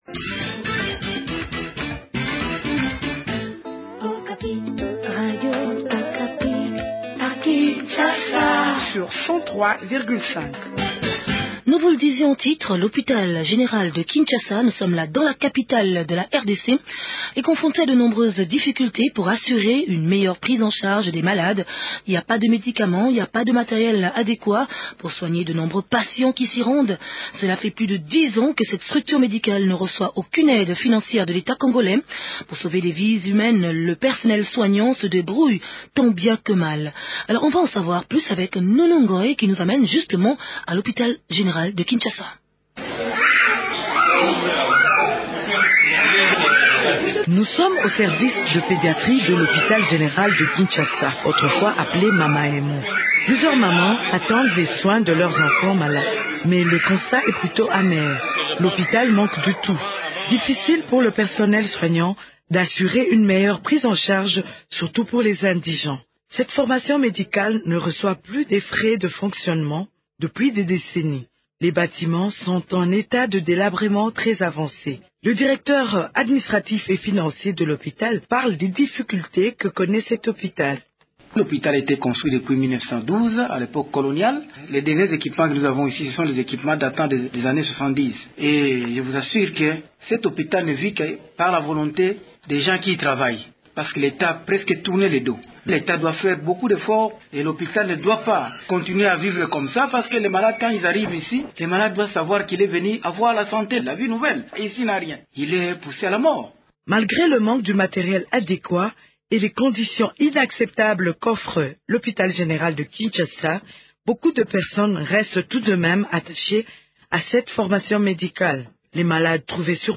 Ferdinand Ntwa, vice-ministre de la Santé évoque les stratégies mises en place par le gouvernement pour réhabiliter le secteur de la santé en RDC.